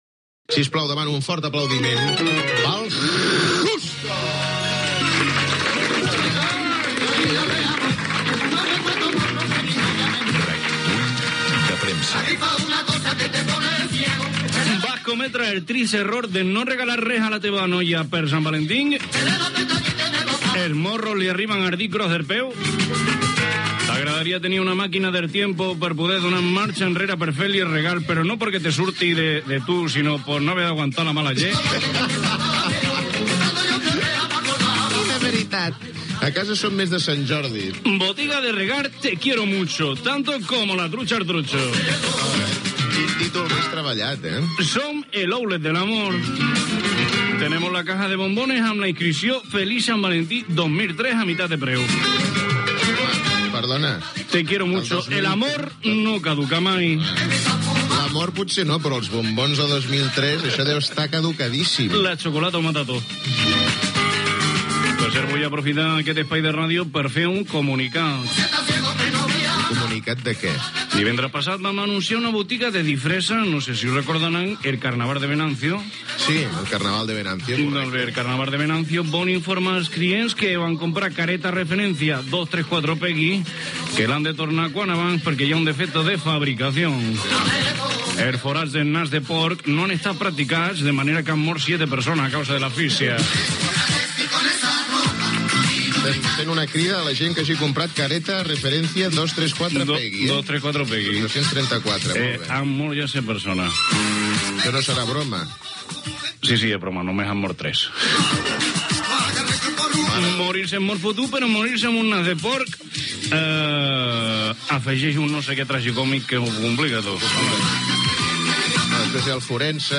Paròdia publicitària de la botiga "Te quiero mucho" i recull de premsa, imitant a Justo Molinero (veu Òscar Andreu)
Entreteniment